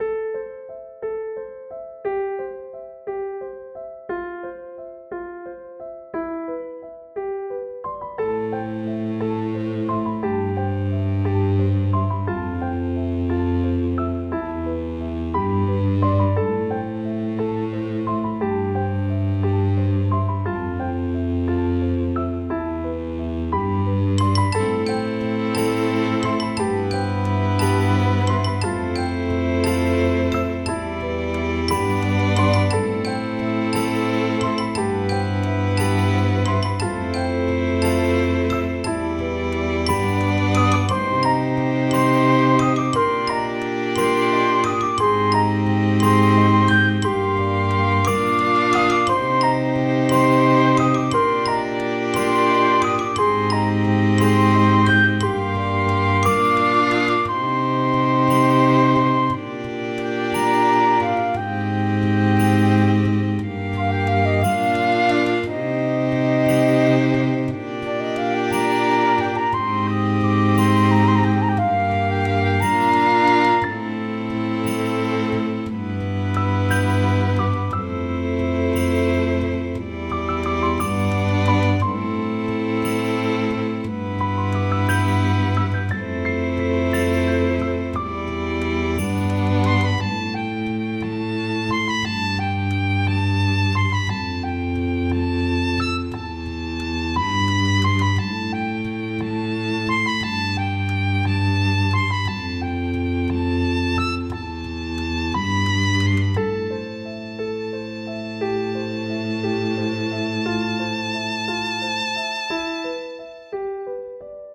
フリーBGM素材- 森の奥の聖域とか。